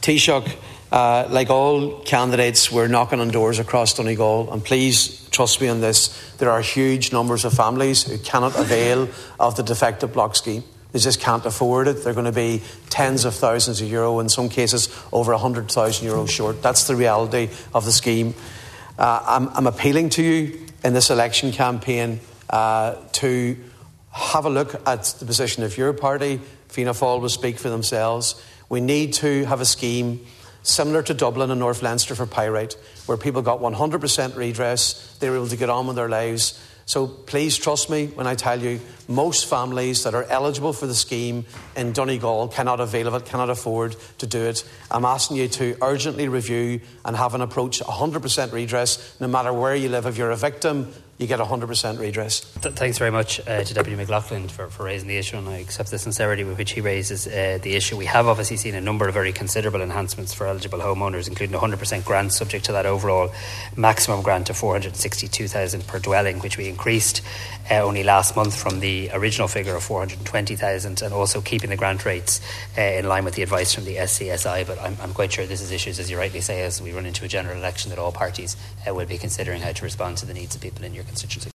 The Dail has heard a plea for an immediate review of the Defective Concrete Block Scheme, with Deputy Padraig MacLochlainn telling the Taoiseach that many affected families are not engaging with the scheme because they can’t afford to.